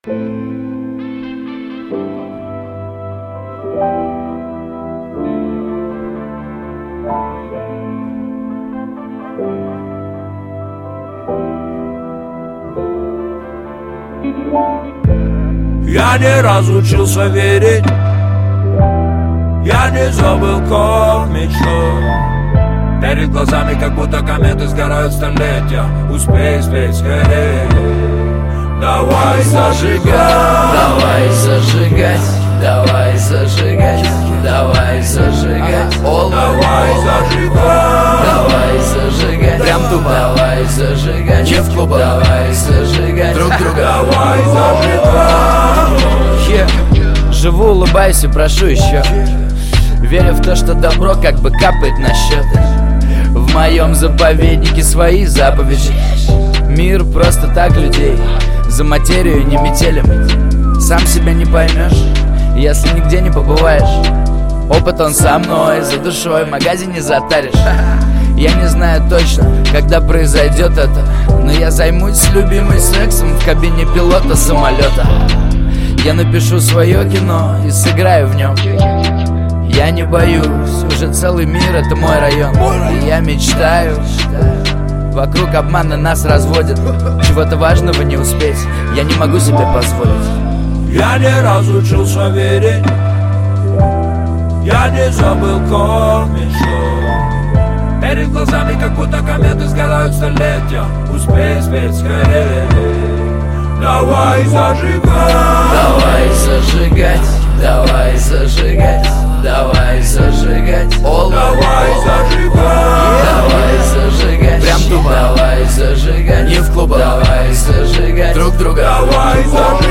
Жанр: Жанры / Русский рэп